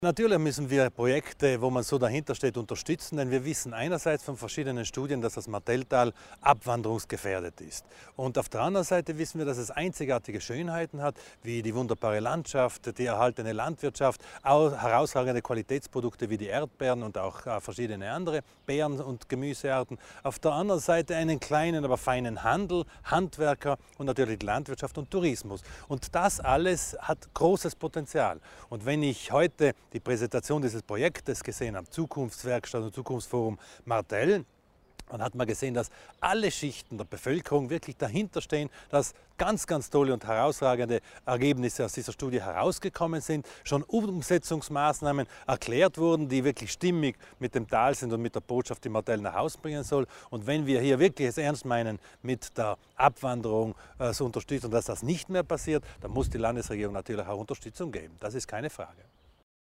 Landesrat Thomas Widmann erklärt die Vorteile des Projekts lebendige Orte